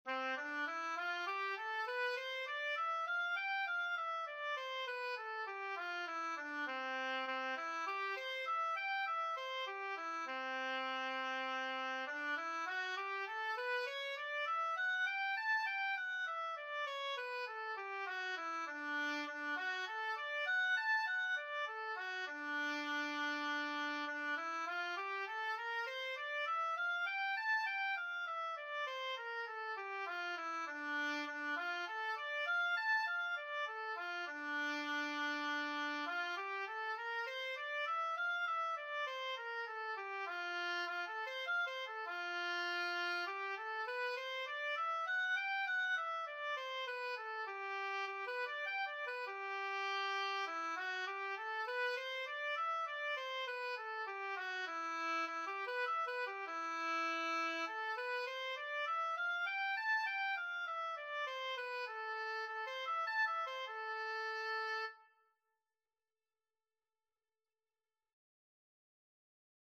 Oboe scales and arpeggios - Grade 2
C major (Sounding Pitch) (View more C major Music for Oboe )
4/4 (View more 4/4 Music)
C5-A6
oboe_scales_grade2_OB.mp3